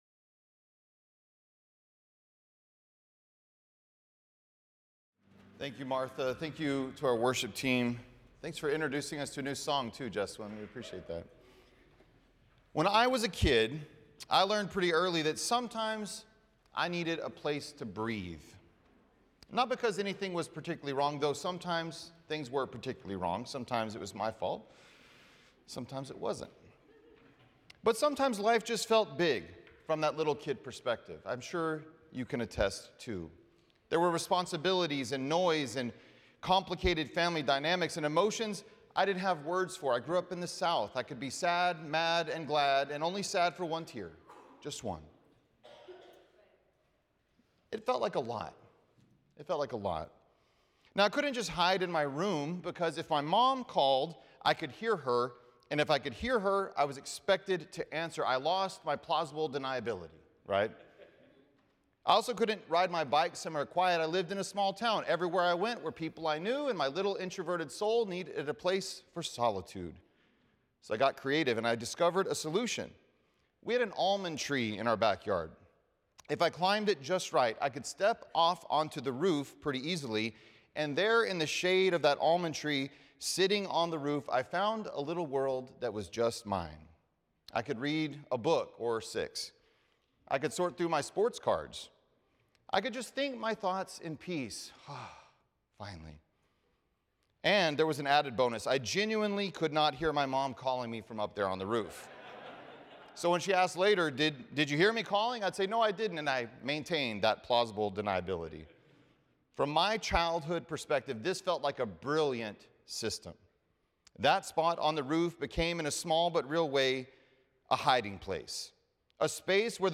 The following service took place on Tuesday, March 17, 2026.